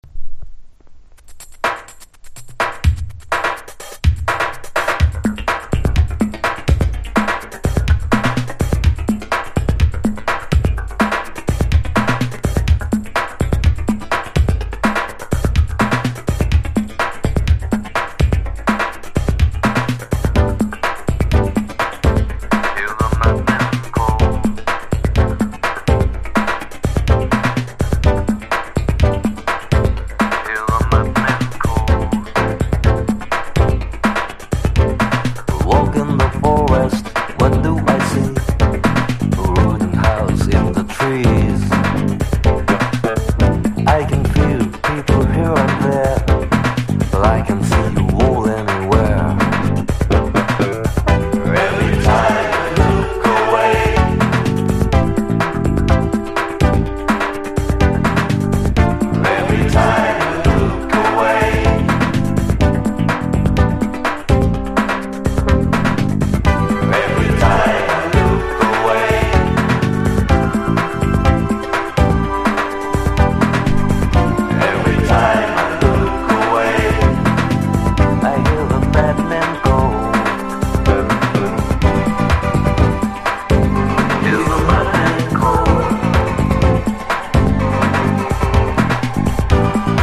エレポップ良作!!